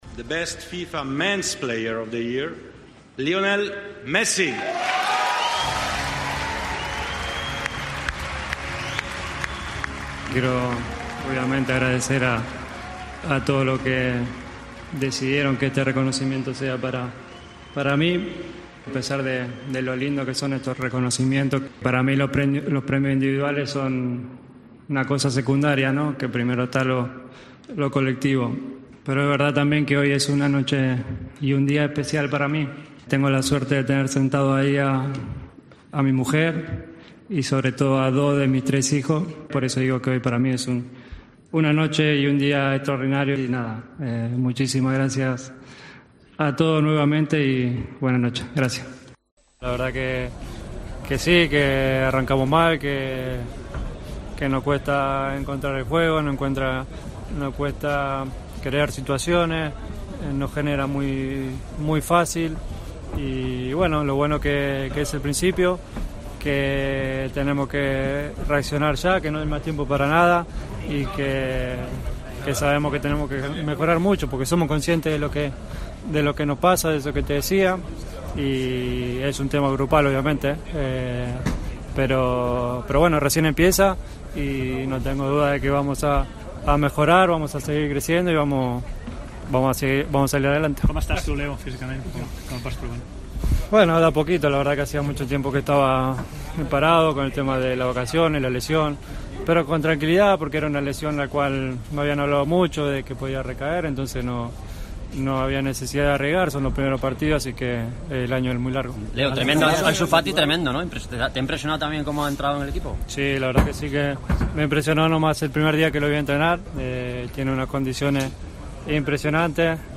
El discurso de Messi tras recibir el 'The Best' en el que se ha emocionado hablando de su familia
El delantero del FC Barcelona se ha impuesto al central holandés del Liverpool Virgil van Dijk y al delantero portugués de la Juventus Cristiano Ronaldo, en la gala organizada por la FIFA en el Teatro de La Scala de Milán (Italia).